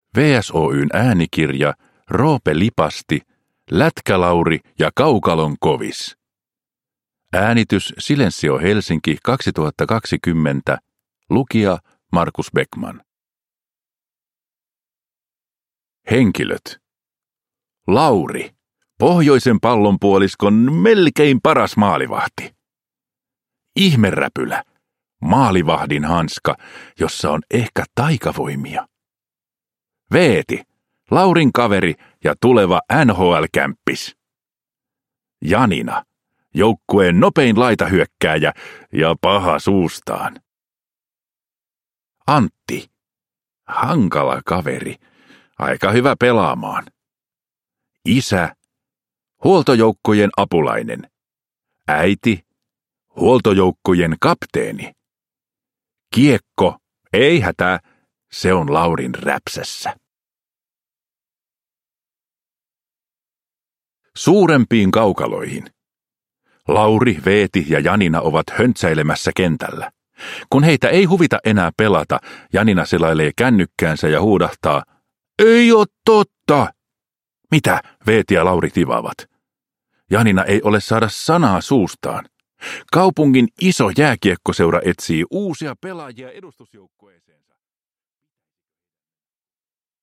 Lätkä-Lauri ja kaukalon kovis – Ljudbok – Laddas ner